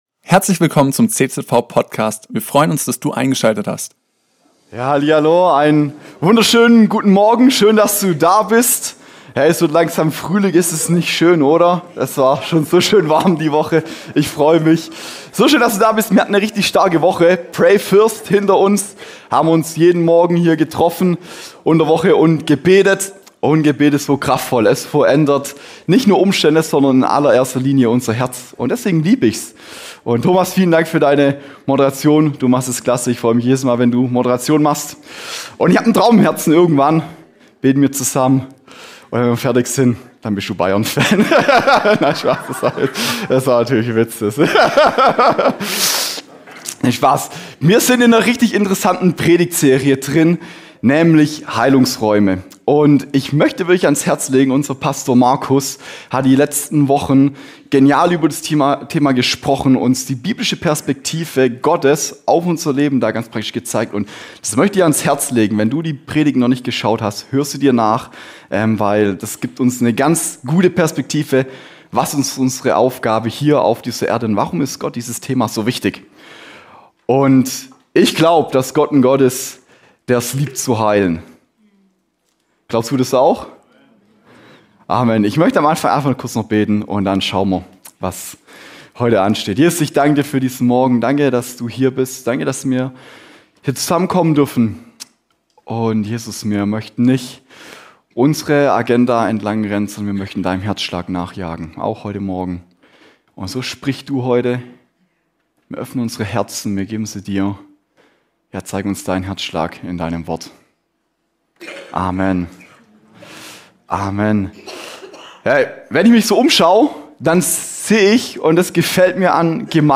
Fortsetzung unserer aktuellen Predigtserie zum Thema Heilung und Heilungsräume.